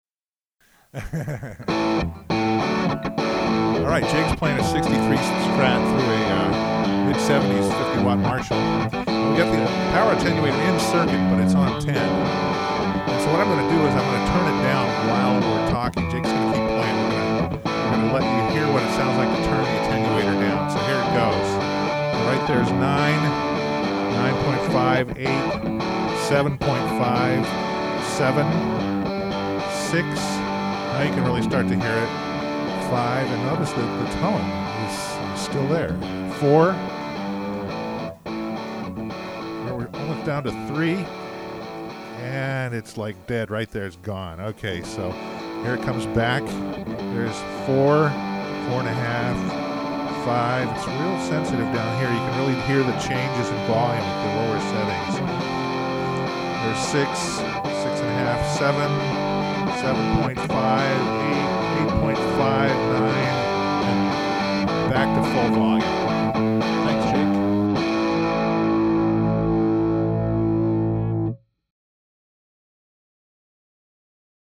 We set up his Marshall head and an attenuator in the control room with us, and we miked a 2x12 open back Marshal cabinet out in the studio. In the first sound file, I adjust the attenuator from all the way up, to all the way down, and back again - while narrating.
'63 Strat, 50 Watt Marshall, Attenuator Zero to 10 (Listen)